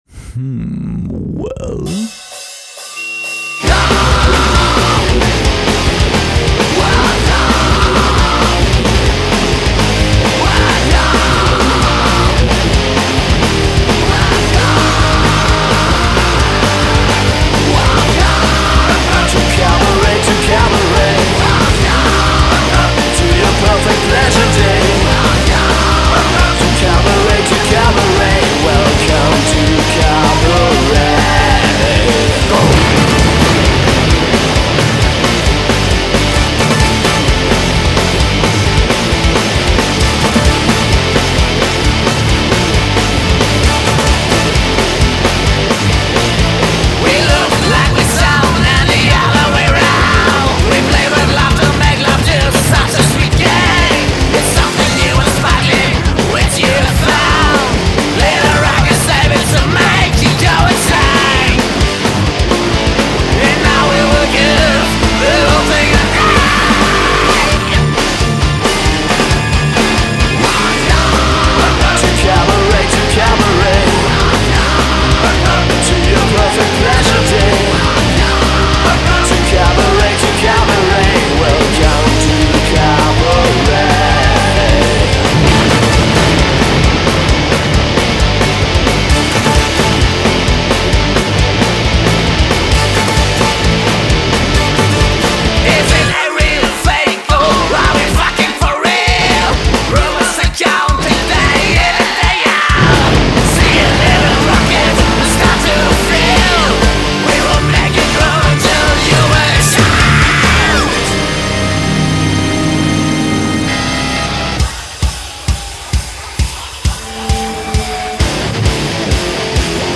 Gesang/Synth
Gesang/Gitarre
Bass